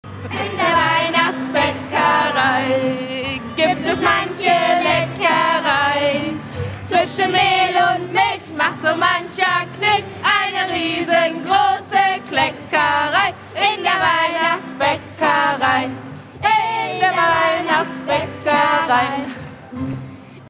Tausende singen im Stadion an der Hafenstraße in Essen Weihnachtslieder. Das Stadion-Singen sammelt Spenden für krebskranke Kinder der Universitätsmedizin.